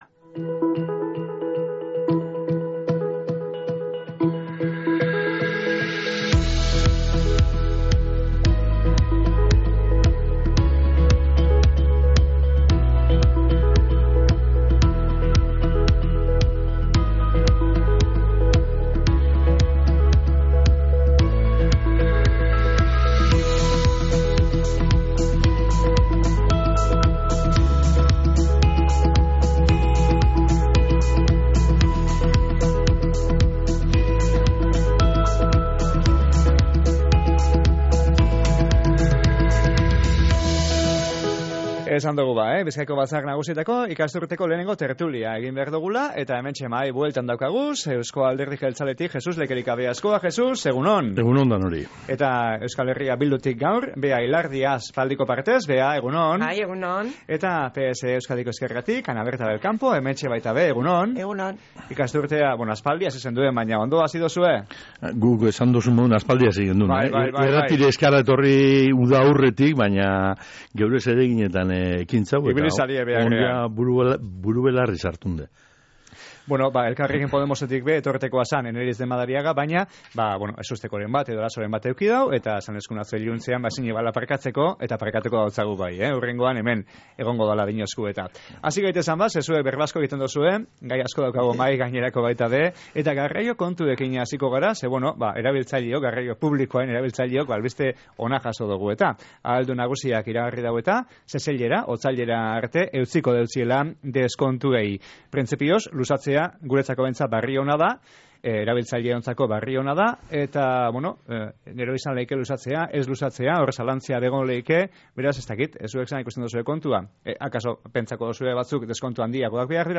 EAJ, EH Bildu eta PSE-EEko ordezkariak batu jakuz gaur Bizkaiko Batzar Nagusien tertuliara
Bizkaiko Batzar Nagusien ikasturteko lehenengo tertulia izan da gaurkoa